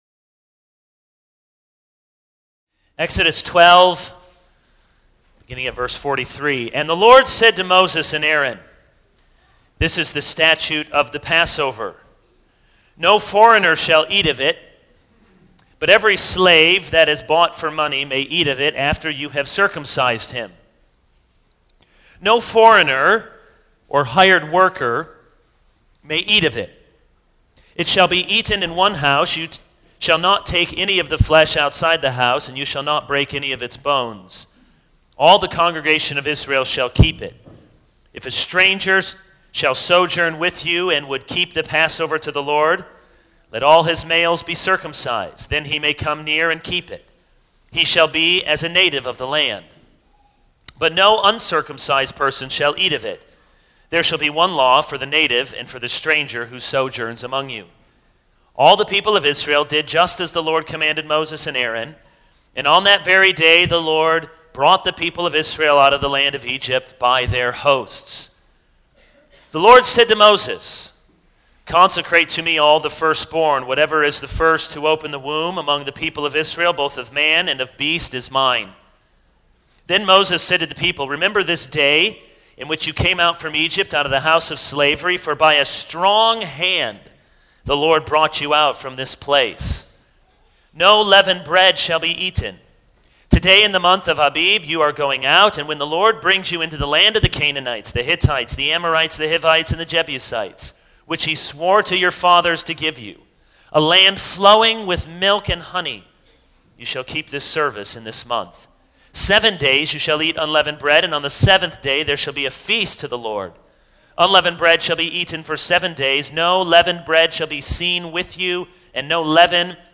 This is a sermon on Exodus 12:43-13:16.